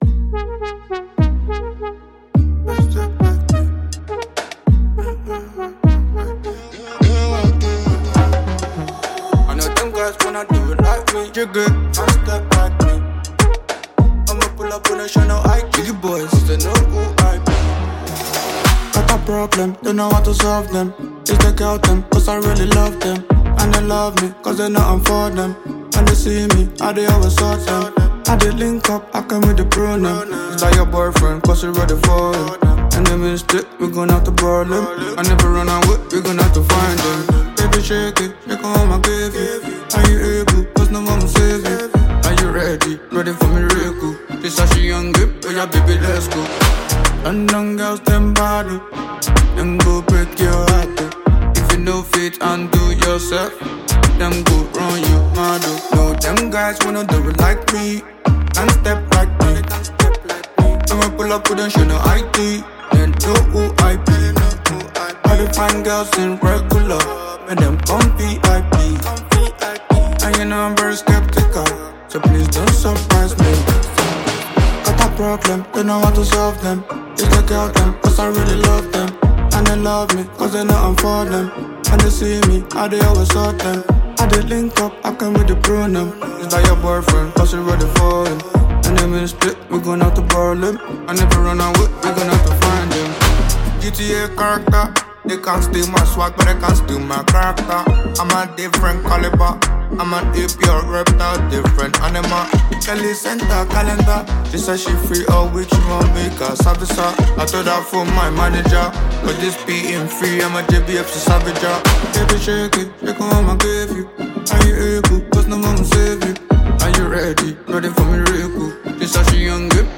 Nigerian Afropop singer and songwriter